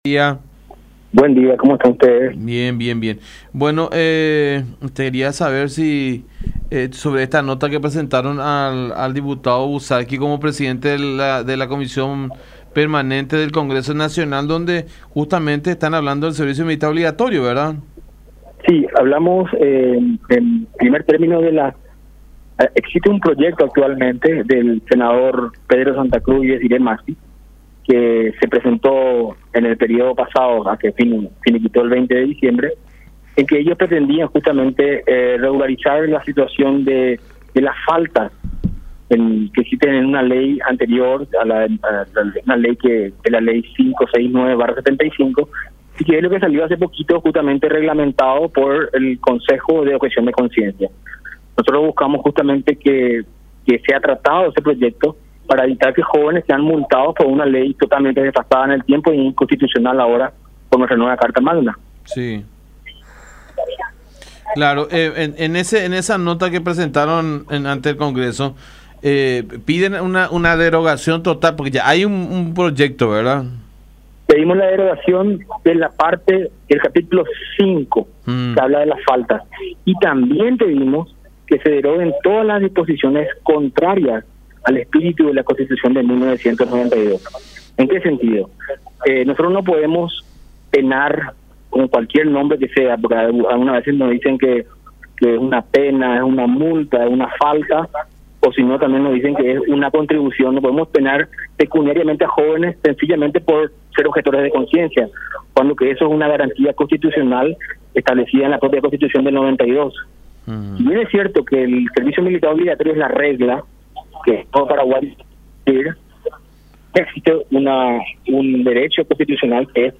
“Pedimos la derogación del Capítulo V (que desglosan las tasas militares por faltas) y todas las disposiciones que van en contra del espíritu de la Constitución de 1992”, expresó en comunicación con La Unión.